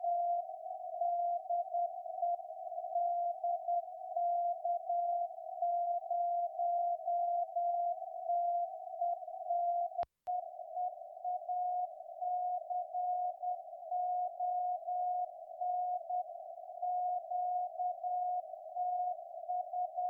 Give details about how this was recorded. Reception on shortwave Mode: CW BW: 150Hz